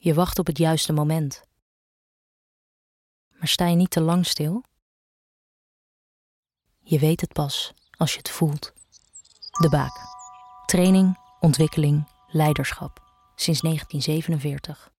Een onderscheidende aanpak die rust en kalmte centraal stelt, met rustige gesproken tekst en momenten die opvallen in een doorgaans drukke radiocommercialomgeving.